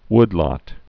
(wdlŏt)